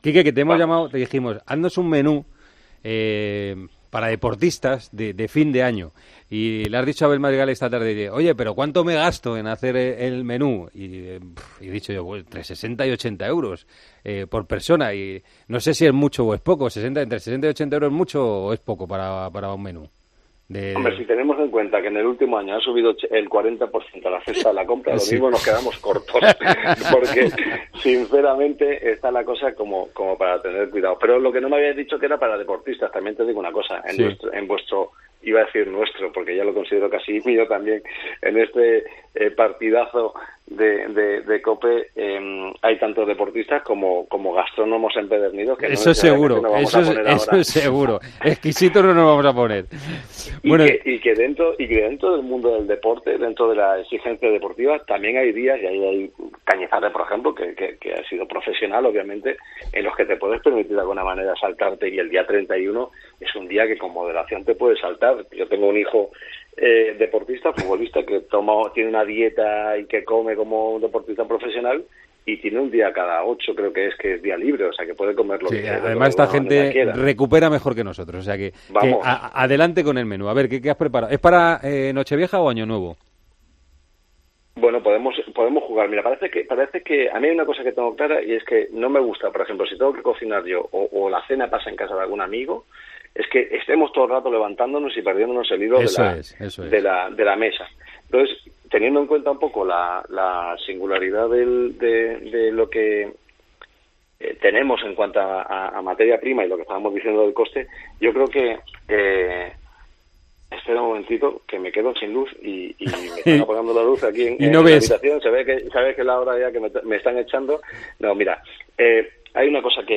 El famoso cocinero, que cuenta con siete estrella Michelín en sus restaurante, pasó este jueves por El Partidazo de COPE y charló con José Luis Corrochano.